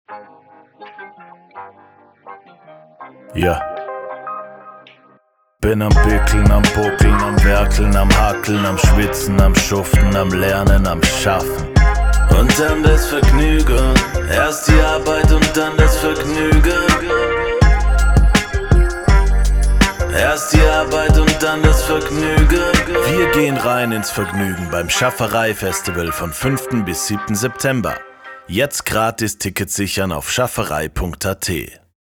Radiospot Schaffarei Festival 2024 – Bronze (Audio)